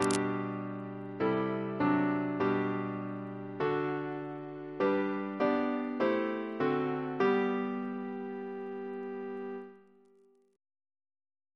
CCP: Chant sampler
Single chant in G Composer: Sir John Stainer (1840-1901), Organist of St. Paul's Cathedral Reference psalters: ACB: 259; H1982: S423; RSCM: 207